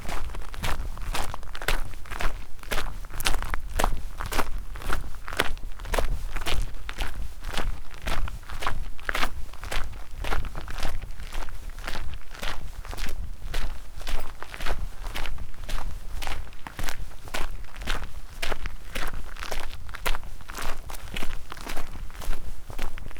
mixkit-crunchy-road-fast-walking-loop-1274.wav